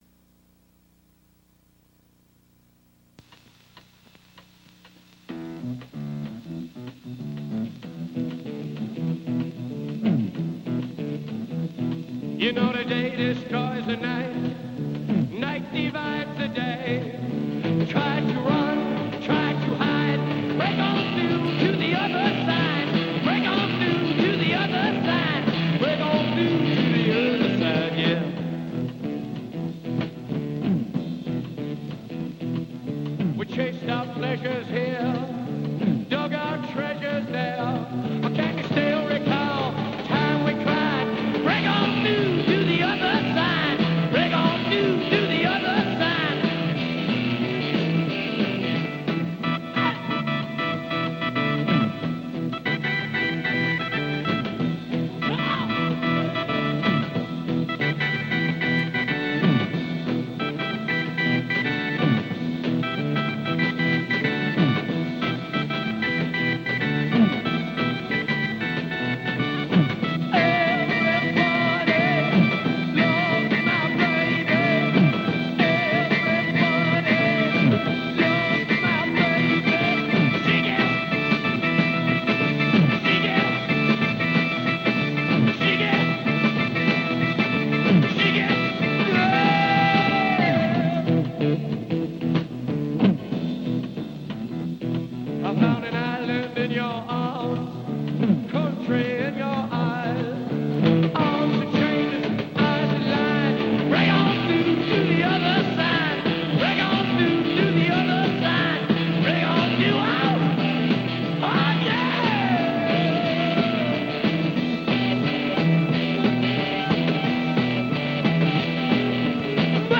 Psychedelic Rock, Blues Rock